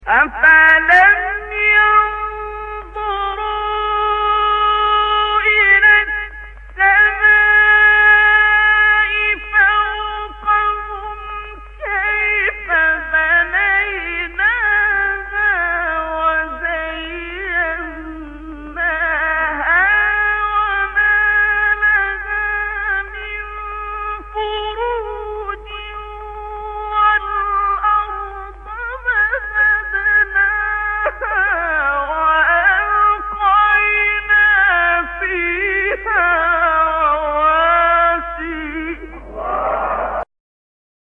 سایت قرآن کلام نورانی - حجاز عبدالباسط (5).mp3
سایت-قرآن-کلام-نورانی-حجاز-عبدالباسط-5.mp3